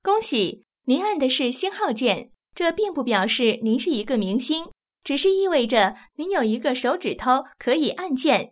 ivr-congratulations_you_pressed_star.wav